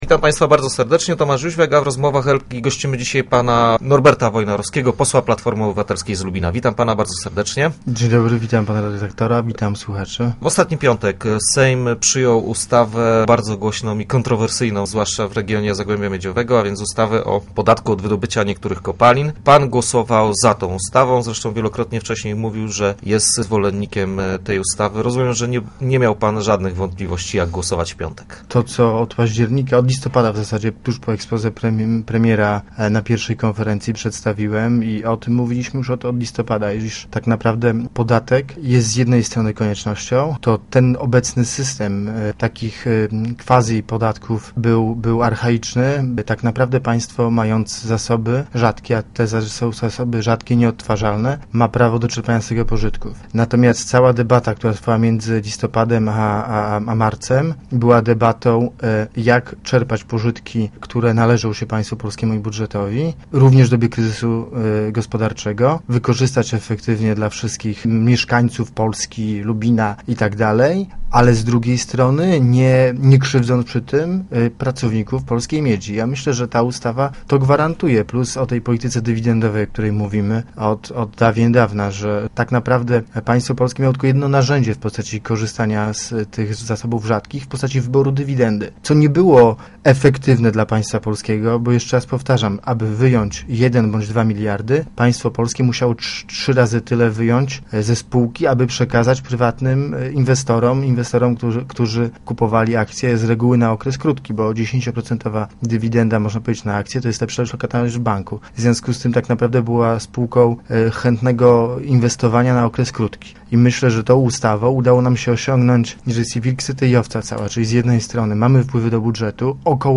W ostatni piątek Sejm przyjął ustawę o podatku od wydobycia niektórych kopalin. Gościem poniedziałkowych Rozmów był lubiński poseł Platformy Obywatelskiej, Norbert Wojnarowski, który głosował za przyjęciem podatku od miedzi i srebra.